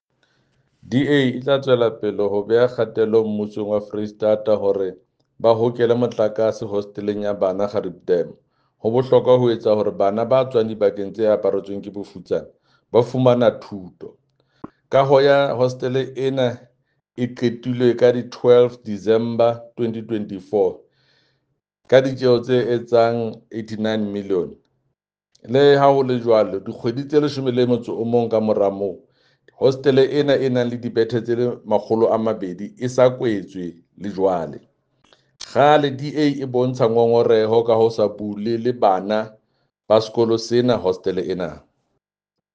Sesotho soundbite by Jafta Mokoena MPL with images here, here and here